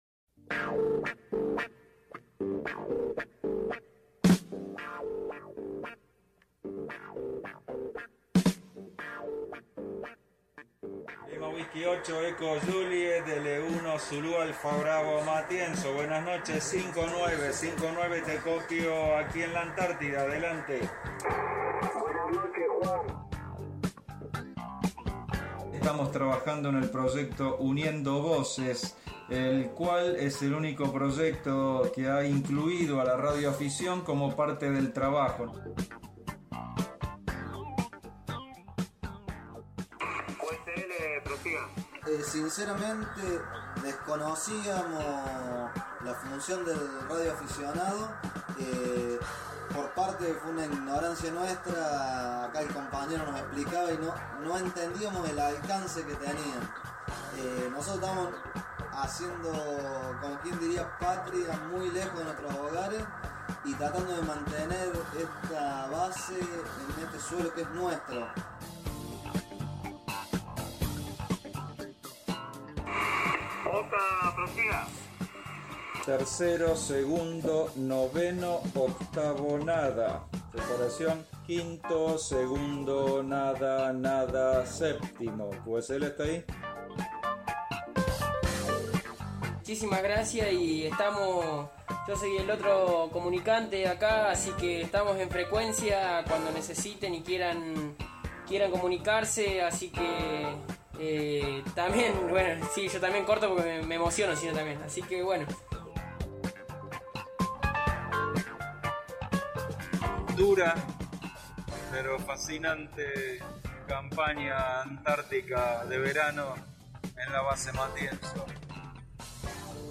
antartida.mp3